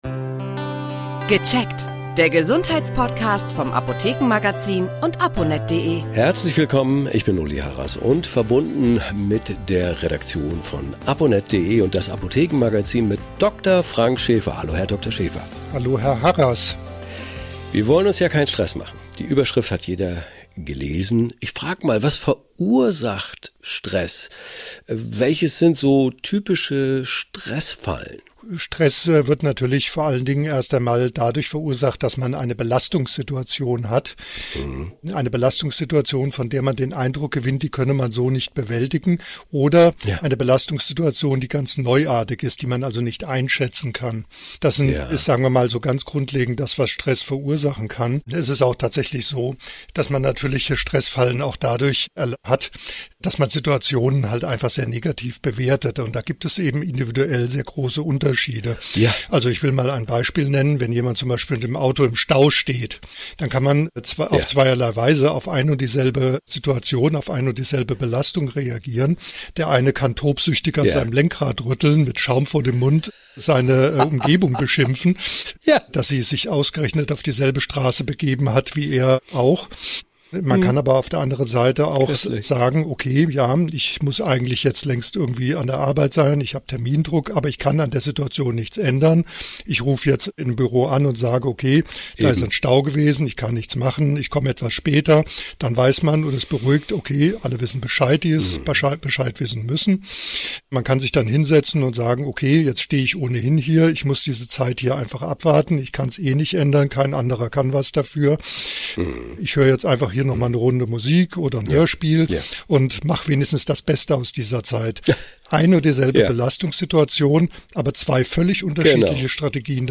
Aber wann wird er gefährlich und was hilft wirklich dagegen? Ein Experte erklärt im Podcast, wie man Stress besser versteht und reduziert.